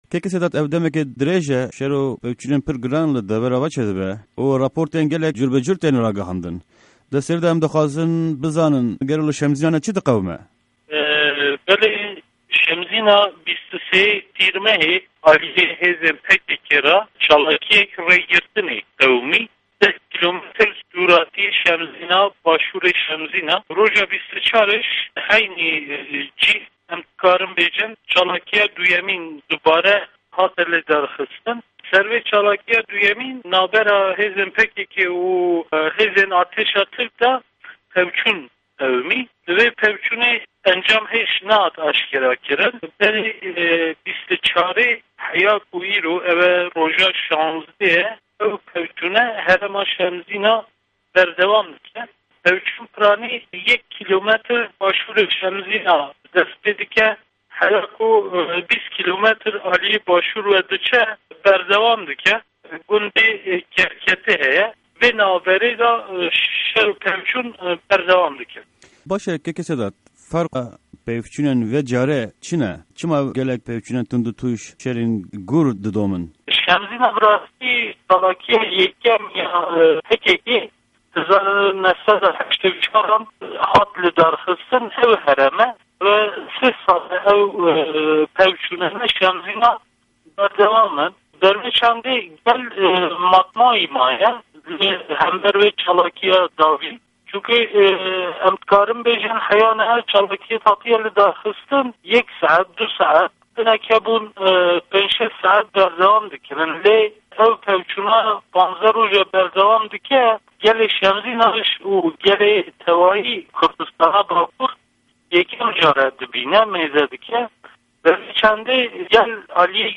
Hevpeyvîn_Sedat Tore